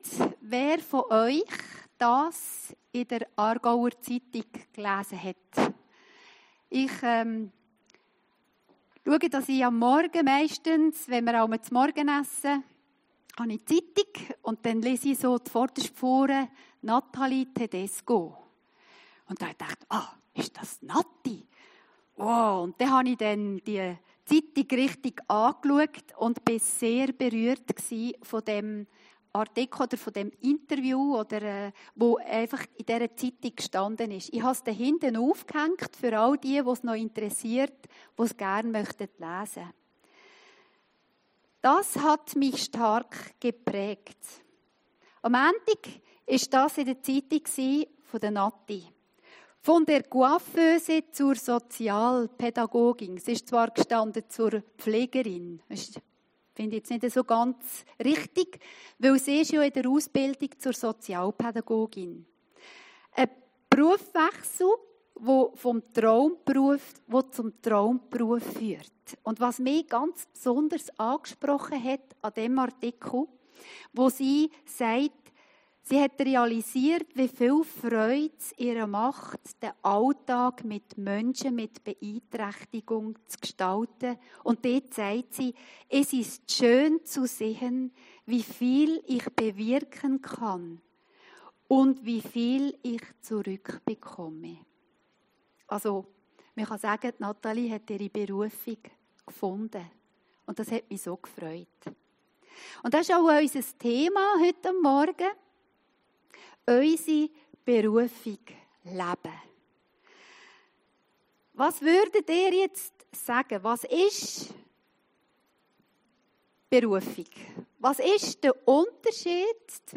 Predigten Heilsarmee Aargau Süd – Epheser 2, 1-10